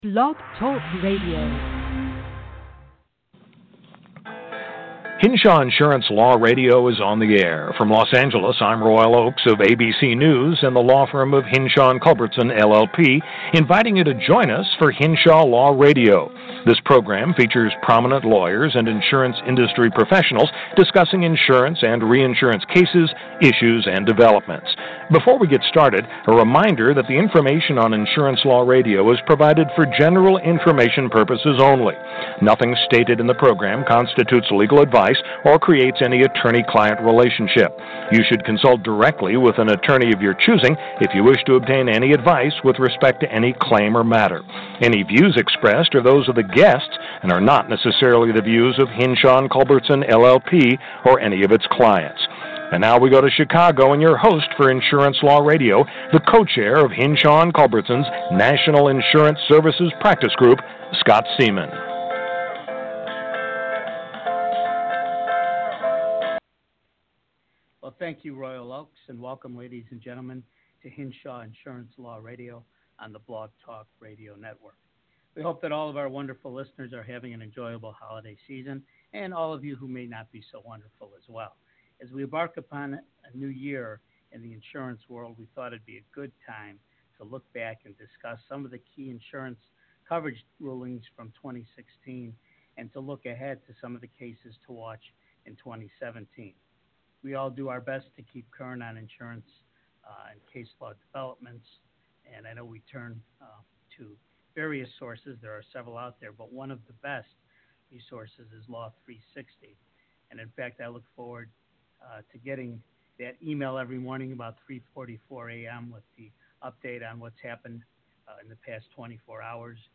Hinshaw Insurance Law Radio Episode 2: An Interview with Insurance Reporter